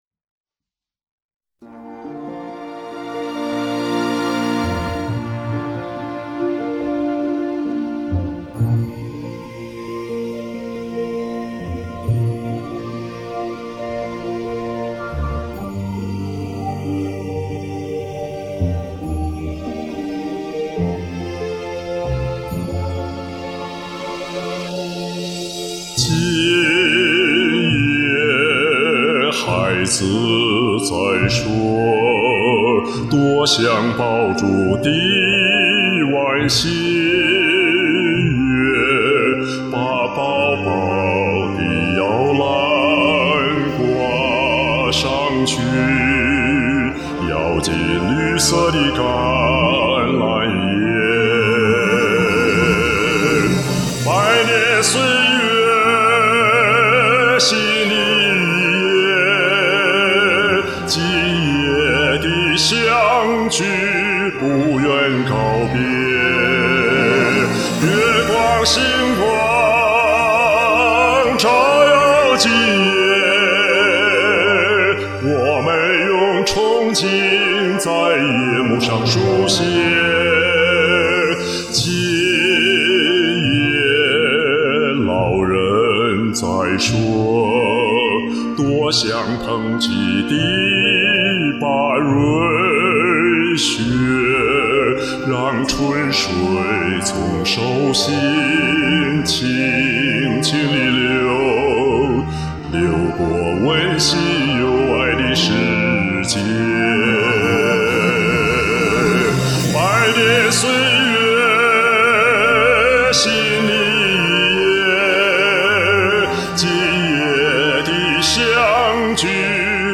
听着很热闹有节日气氛
F调伴奏
清亮的好嗓音，深情的高水平演唱。